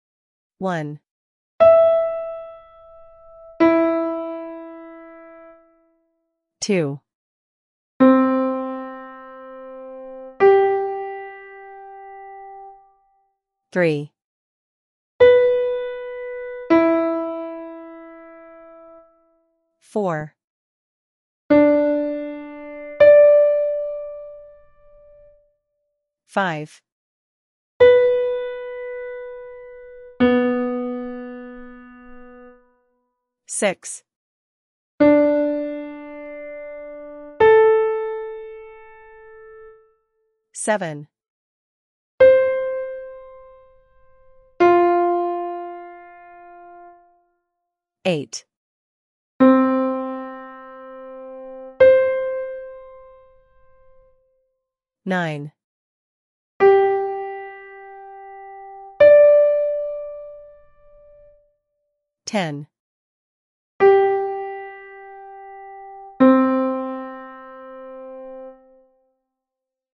Identifying 5th and 8va intervals